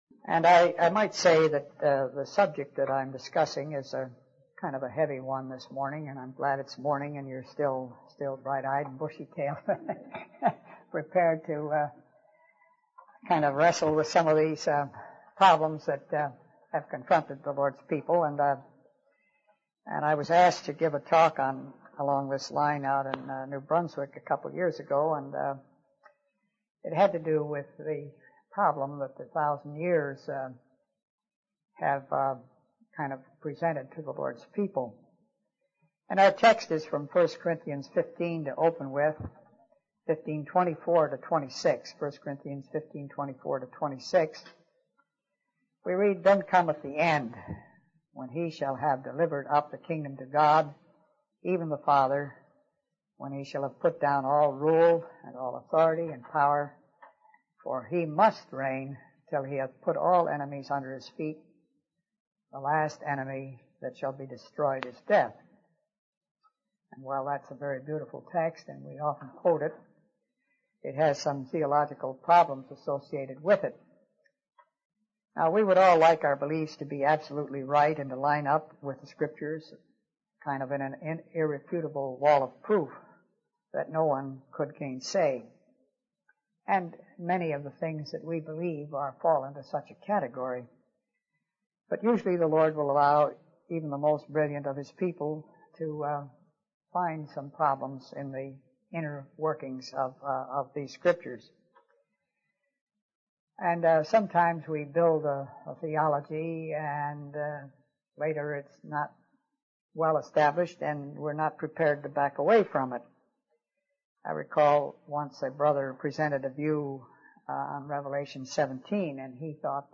From Type: "Discourse"